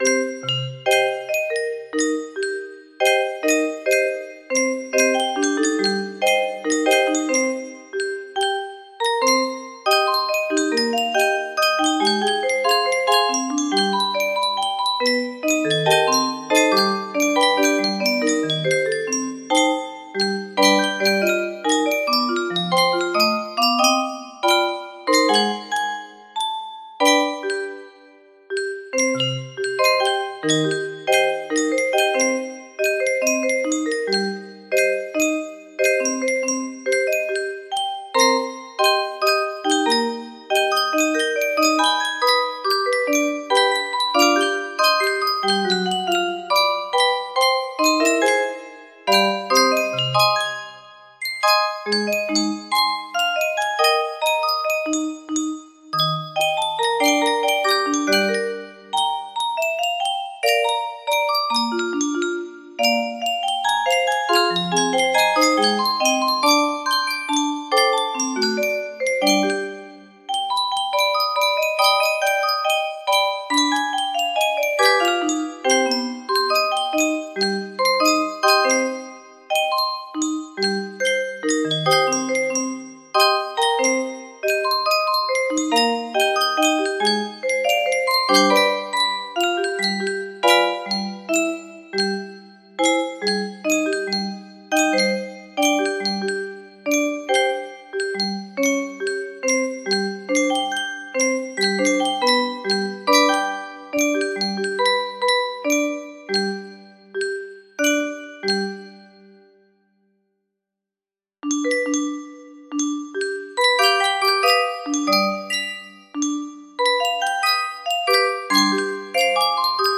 ‧₊˚♪ 𝄞₊Rachmaninoff - Prelude in G Minor (Op. 23 No. 5)‧₊˚♪ 𝄞₊ music box melody
Full range 60
So ive turned er down to 70 to let those notes ring out and be appreciated a bit more.
This midi was my attempt to arrange it for musicbox a bit more "mellow" and less "anxy" if you will lol